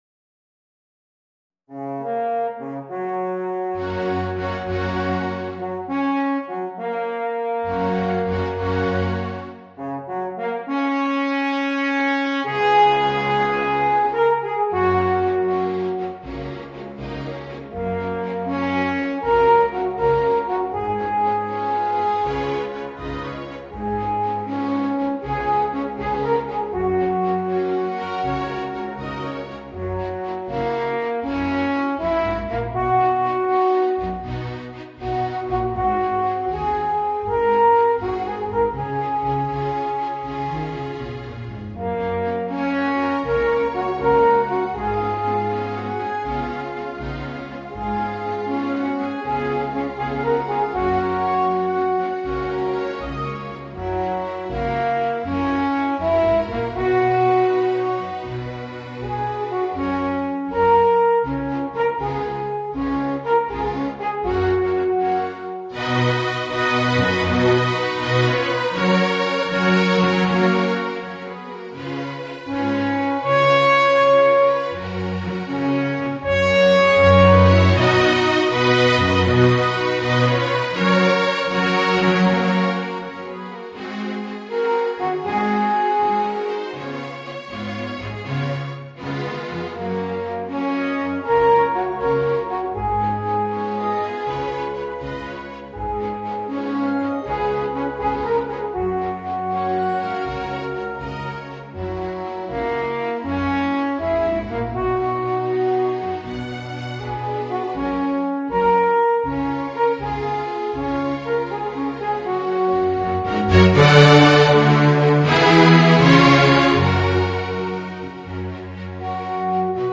Alphorn in Gb & Orchestra
Besetzung: Sinfonieorchester